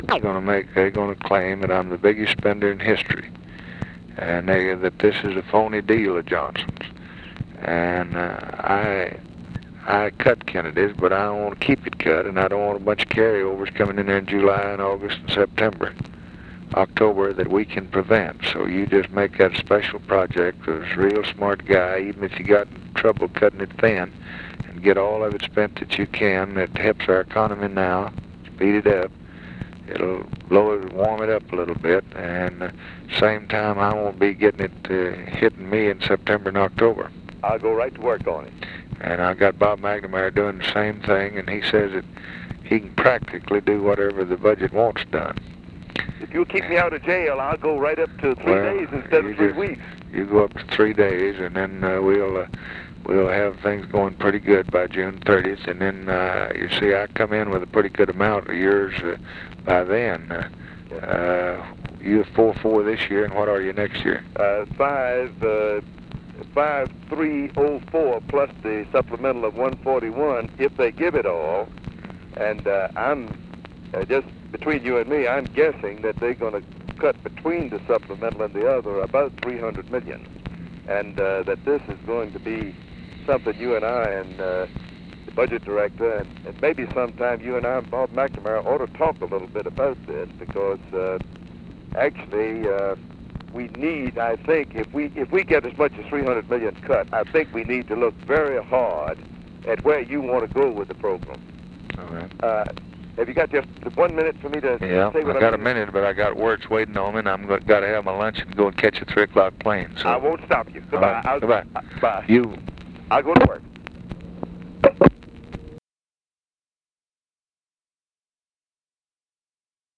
Conversation with JAMES WEBB, April 23, 1964
Secret White House Tapes